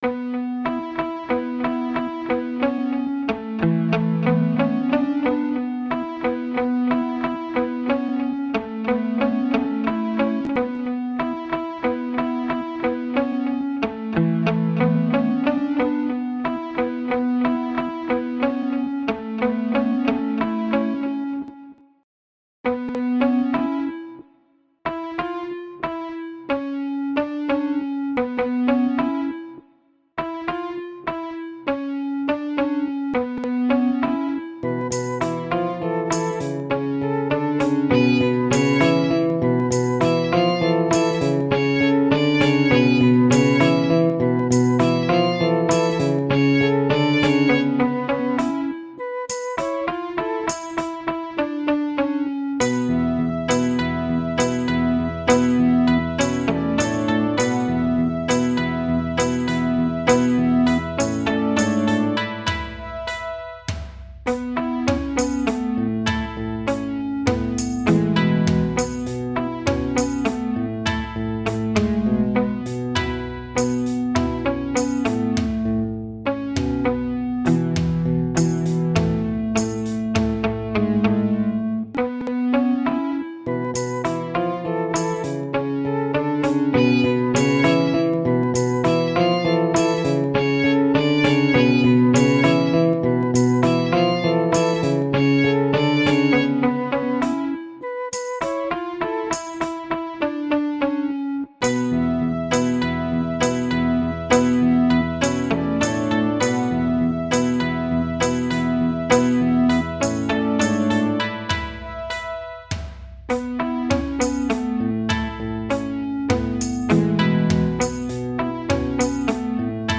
new midi of a pop song i wrote ten ish yrs ago